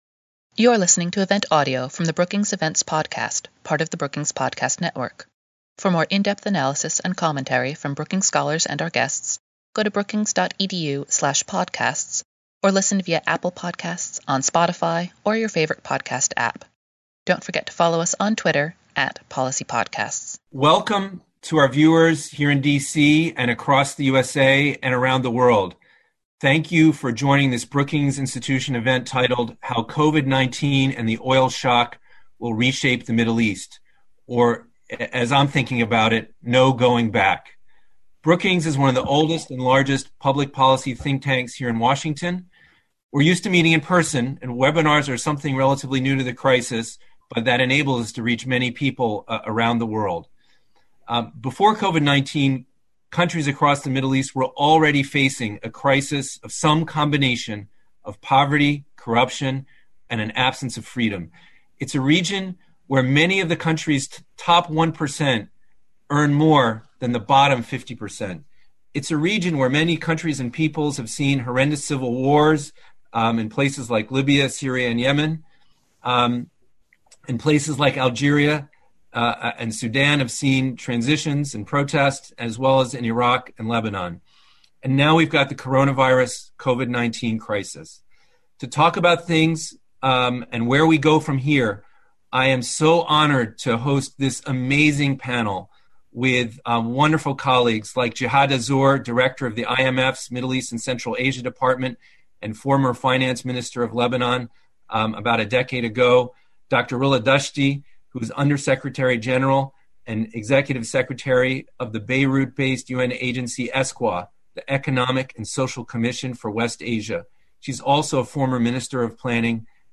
On June 1, the Brookings Center for Middle East Policy hosted a virtual panel event to discuss how COVID-19 is likely to reshape the Middle East.